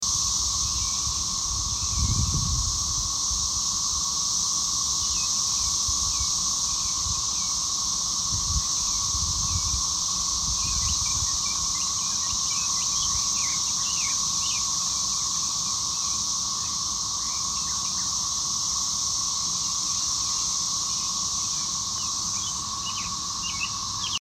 This is the very loud ambient noise in my backyard right now coming from the cicadas.